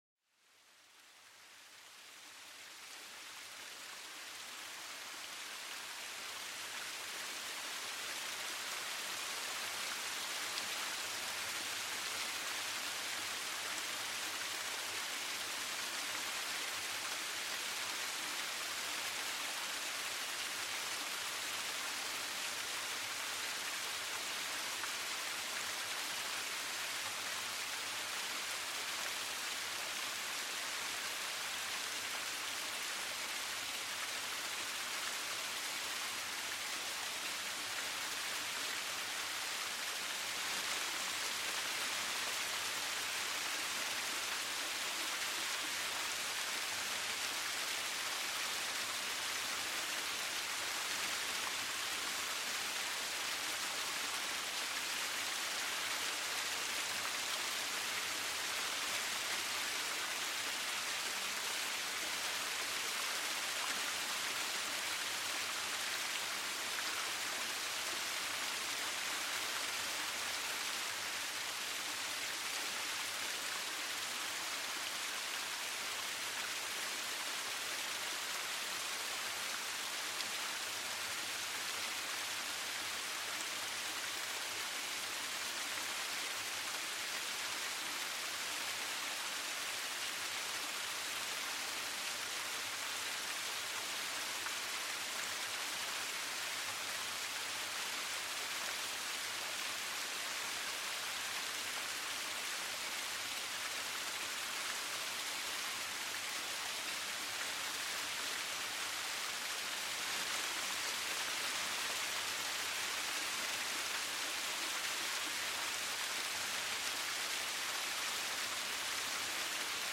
Plongez dans l'ambiance sereine d'une cascade en pleine nature. Laissez le son de l'eau qui s'écoule vous transporter vers un état de relaxation totale.